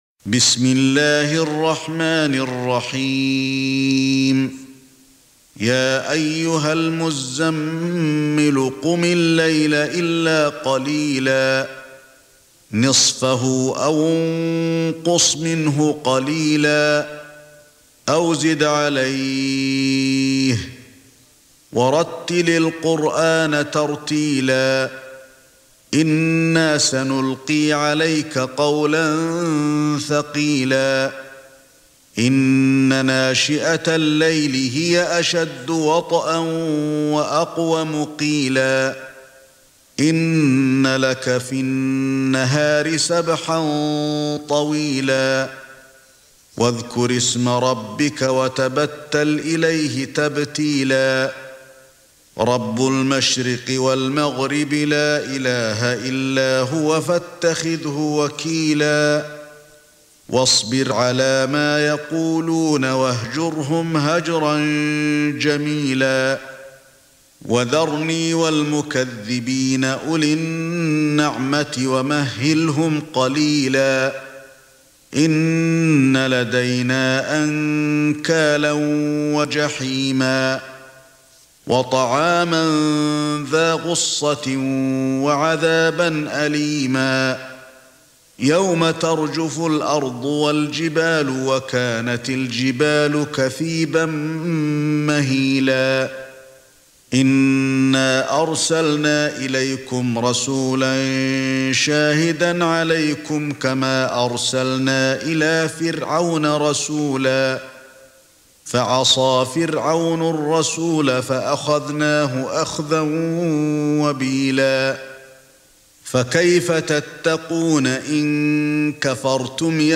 سورة المزمل ( برواية قالون ) > مصحف الشيخ علي الحذيفي ( رواية قالون ) > المصحف - تلاوات الحرمين